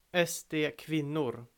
The SD Women (Swedish: SD-kvinnor [ˈɛ̂sːdeːˌkvɪnːʊr]
Sv-SD-kvinnor.ogg.mp3